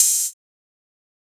TM-88 Hats [Open Hat 2].wav